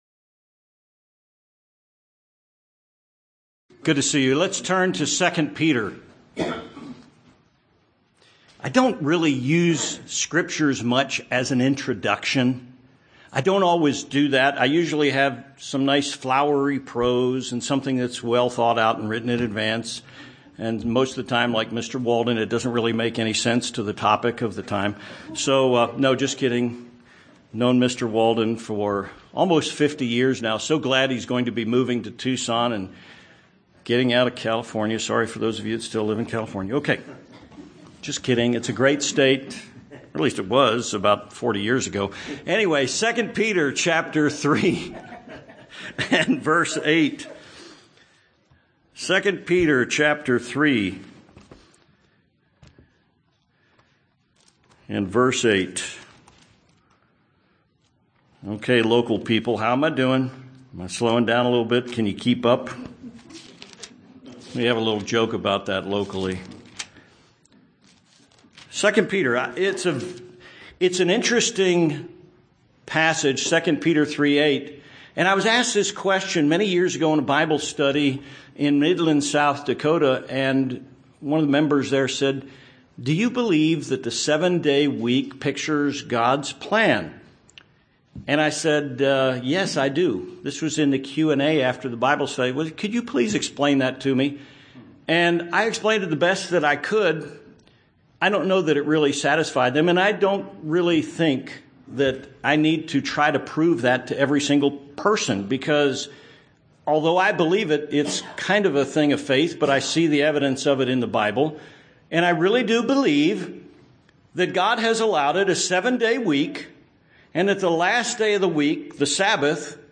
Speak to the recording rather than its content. Given in Tucson, AZ